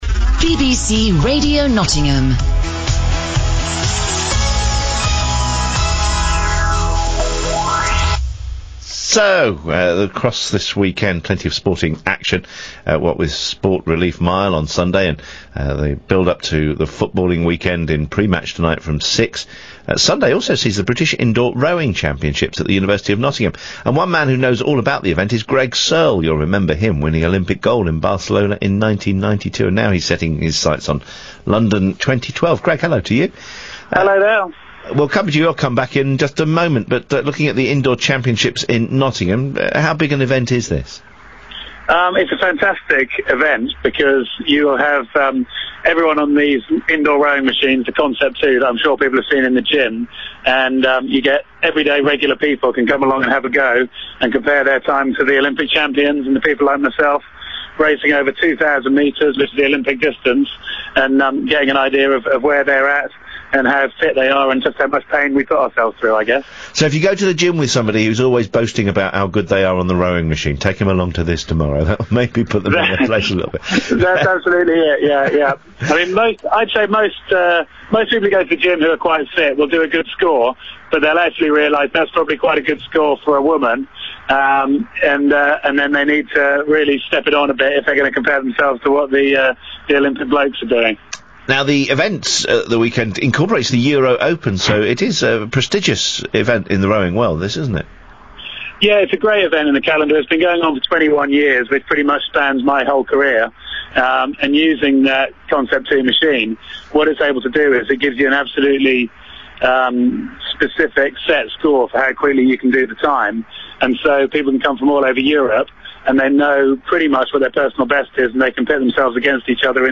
Greg Searle interviewed on BBC Radio Nottingham ahead of BIRC 2012
greg_searle_bbc_nottingham.mp3